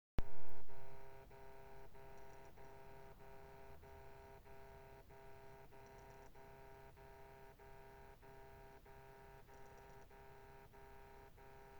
Bruit récurrent unité intérieure Mitsubishi PLFY P25 VFM E
Sur des unités récentes installées cette année sur un bâtiment tertiaire j'ai un son périodique en mode chaud ou froid sur les unités intérieures.
Le son dure environ 2 minutes 30 et s’arrête 40 secondes avant de recommencer.
Ce son n'est pas trop fort mais devient pesant lorsqu'il n'y a pas de bruit dans les bureaux.
Plutôt que vous le décrire, j'ai effectué un petit enregistrement au magnétophone que je joins à ce post (185 ko).
bruit-mitsubishi-plfy-p25-vfm-e.mp3